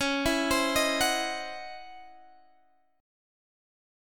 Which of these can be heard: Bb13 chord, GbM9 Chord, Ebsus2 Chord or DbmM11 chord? DbmM11 chord